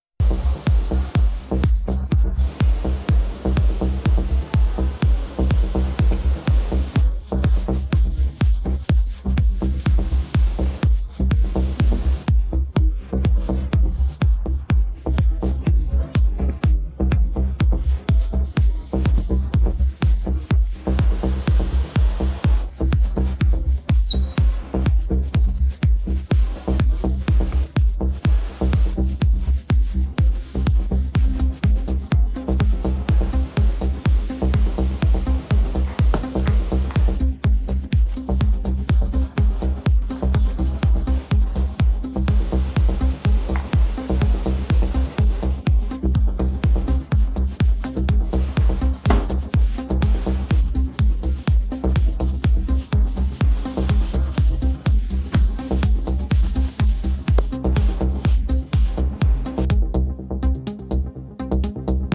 - Muzyka elektroniczna
Stary kawałek, chyba trance.